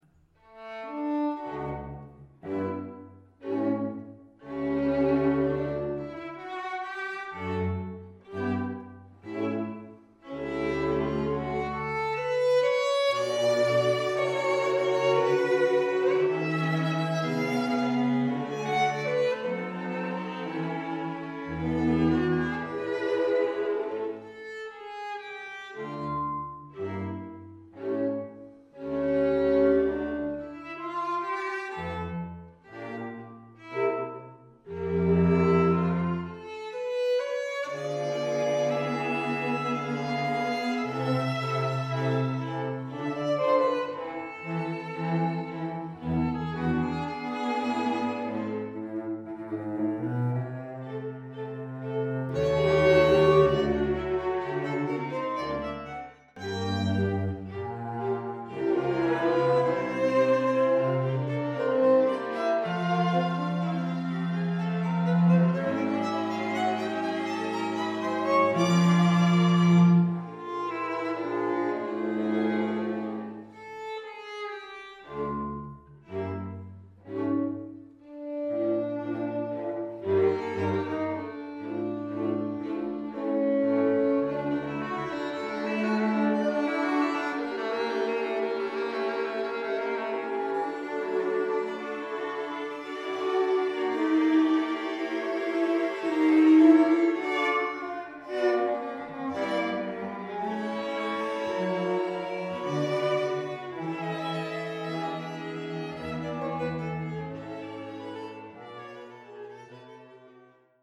For 2 Violins, 2 Violas and Violoncello